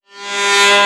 VEC3 FX Alarm 07.wav